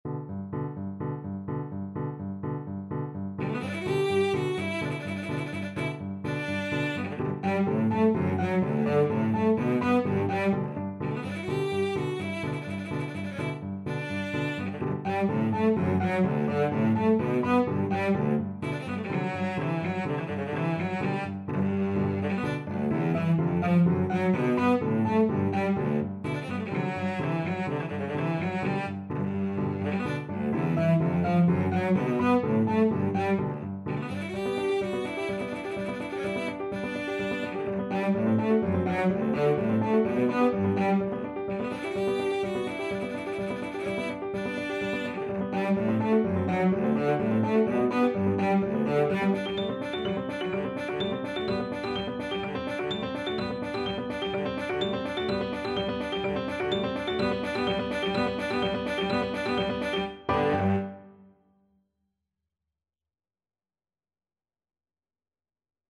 Allegro moderato (=126) (View more music marked Allegro)
4/4 (View more 4/4 Music)
Classical (View more Classical Cello Music)